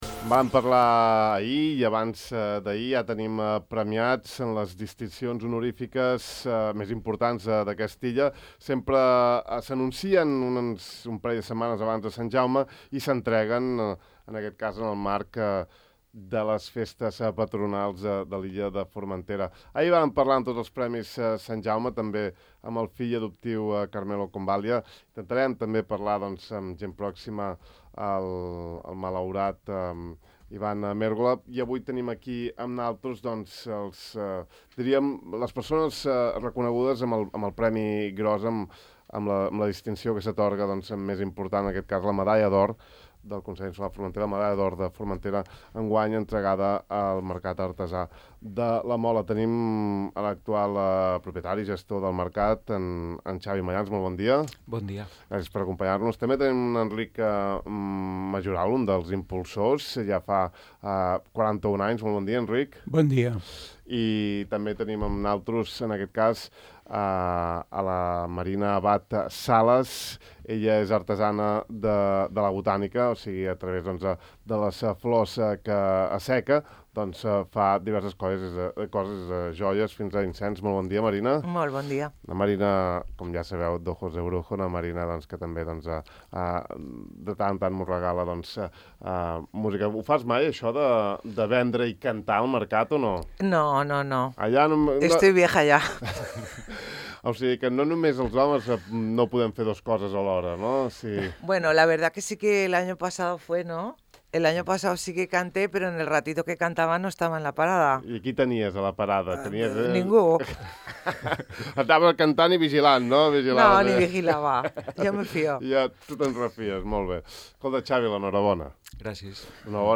En aquesta entrevista